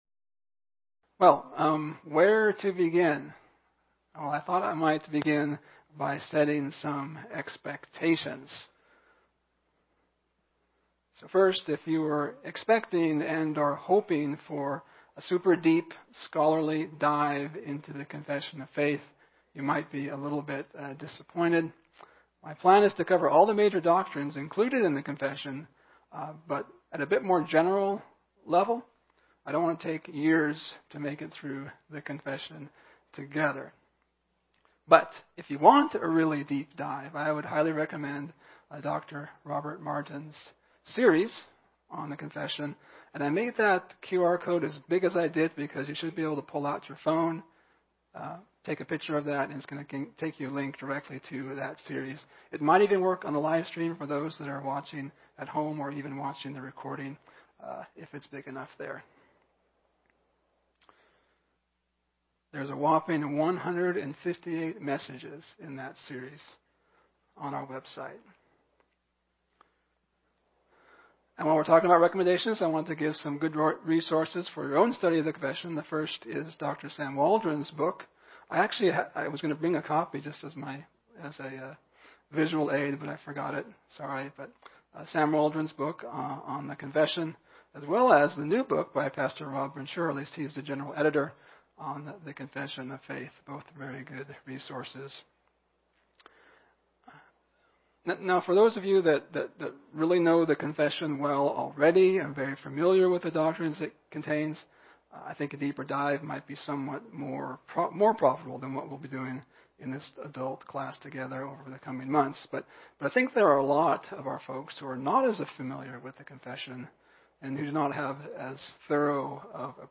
1689 Confession Essentials Service Type: Sunday School « Introducing the Epistle of Joy A Model of Joy-Filled Prayer